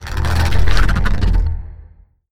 Pets will also make unique noises and sounds when idle, adding more depth and personality to each pet individually!
Can you guess which pet sound this belongs to?